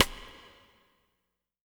BW STICK02-R.wav